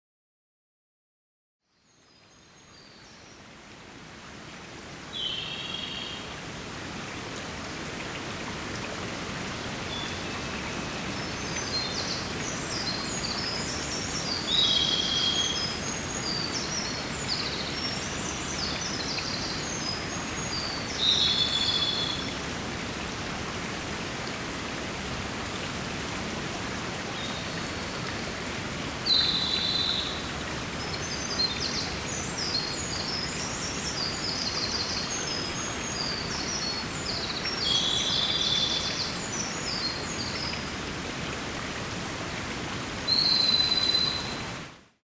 森の音（※５）など）が提示された際、被験者の脳波にアルファ帯の優位化が認められたという報告があります（例：Grassini et al., 2022）。